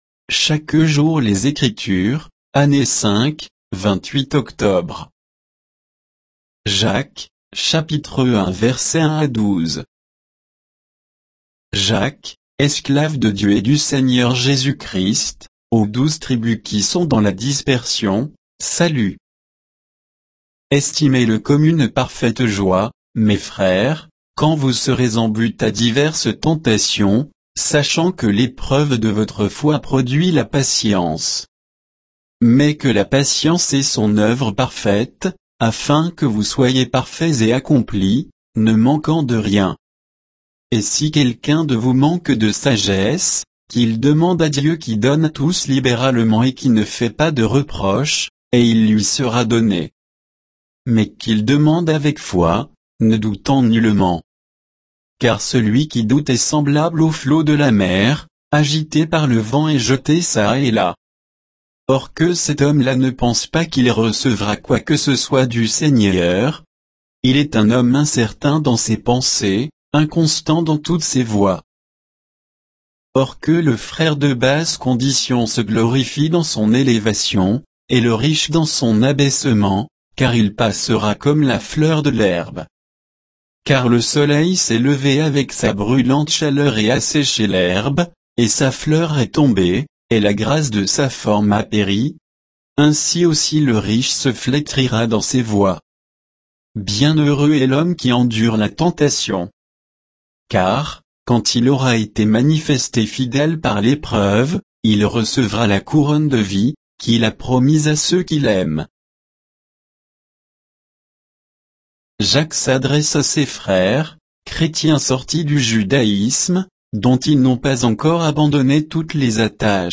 Méditation quoditienne de Chaque jour les Écritures sur Jacques 1, 1 à 12